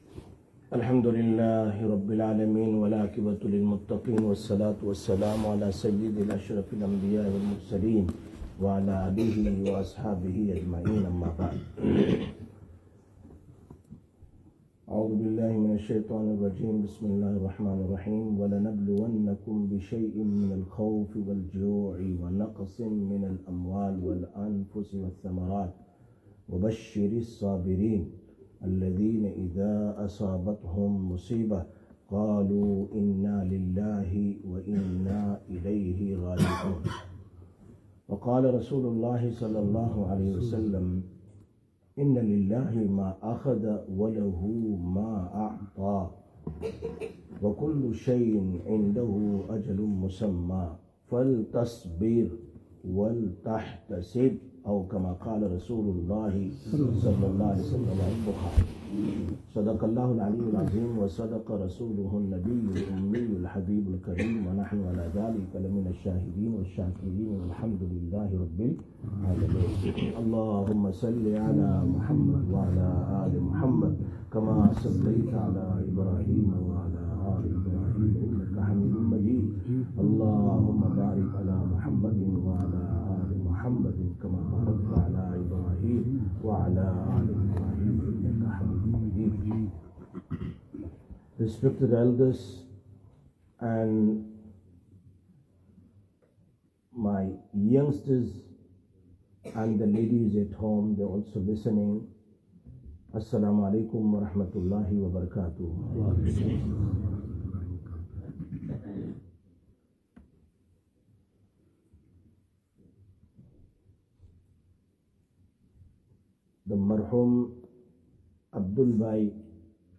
07/09/2025 Masjid ur Rashideen